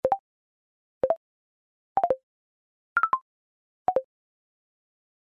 maybe the tritone could be for errors, etc.